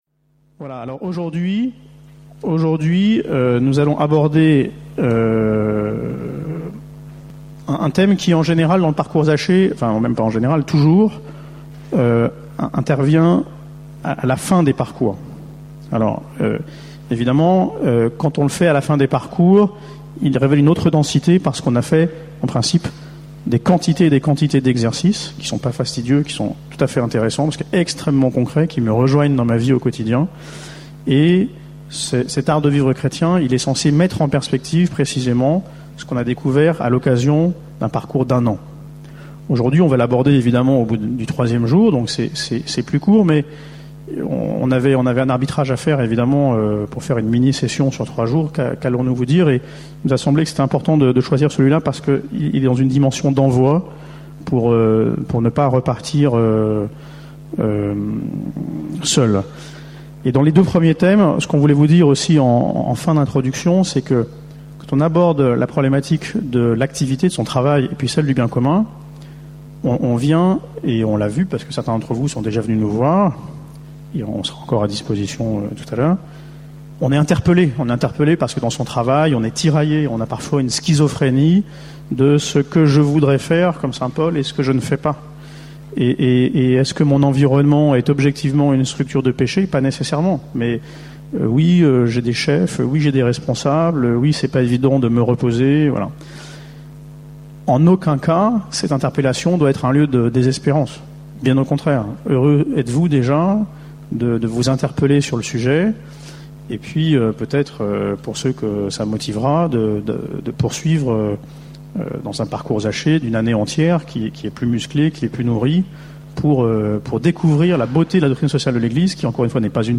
Enseignement
Paray-le-Monial, du 12 au 17 juillet 2013
Format :MP3 64Kbps Mono